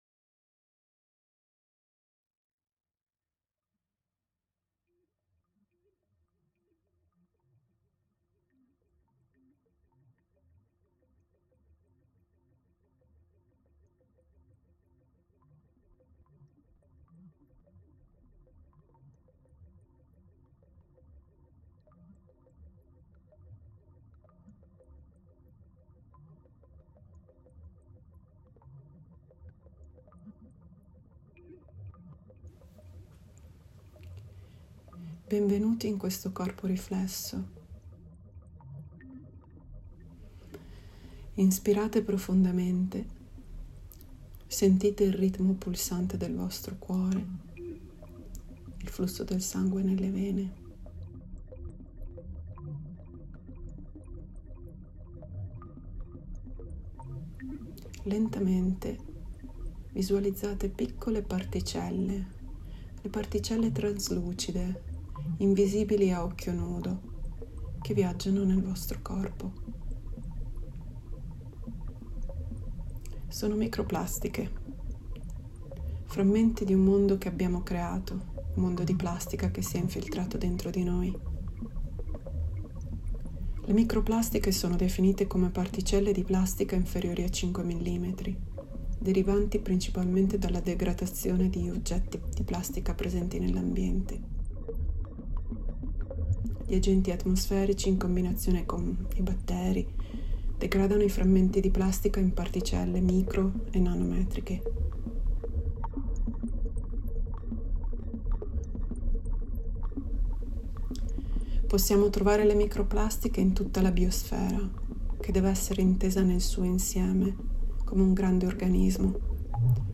plasticenta_meditaz.mp3